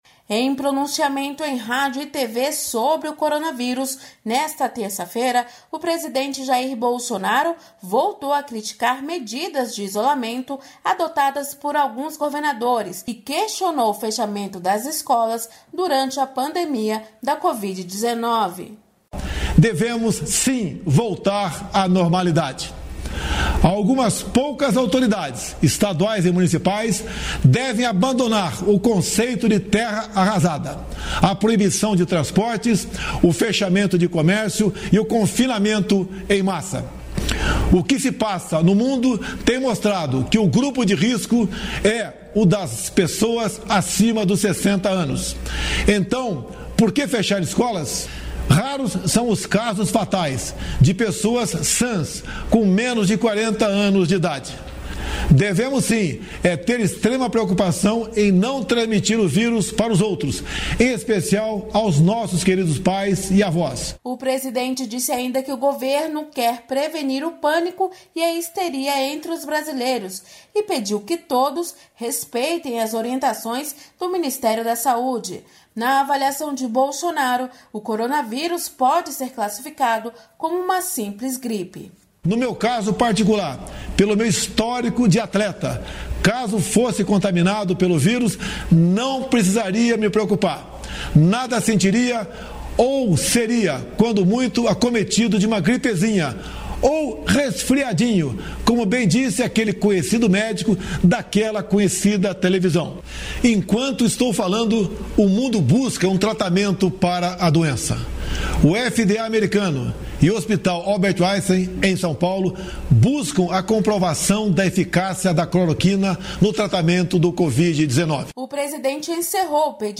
Em pronunciamento, Bolsonaro critica governadores e fechamento de escolas